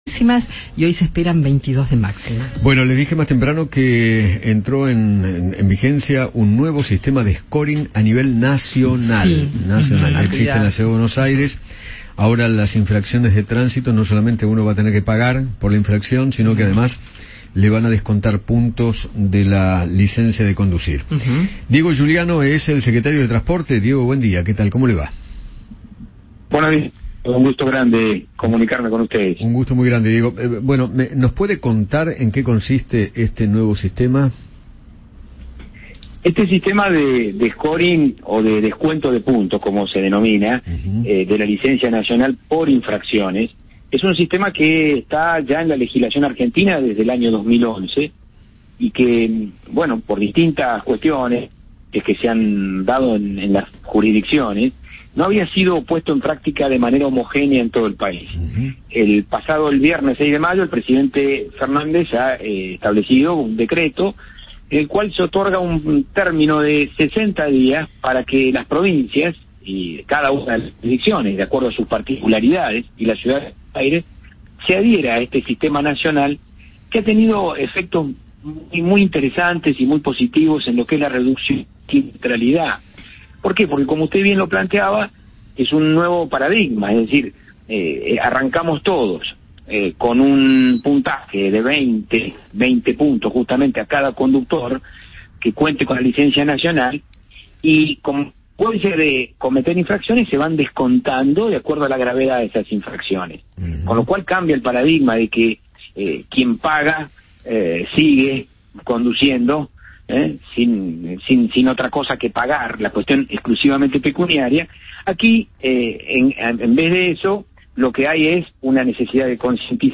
Diego Giuliano, secretario de Transporte, habló con Eduardo Feinmann sobre la puesta en práctica de un sistema de scoring a nivel nacional que descontará puntos en la licencia de conducir a aquellos que cometan infracciones de tránsito.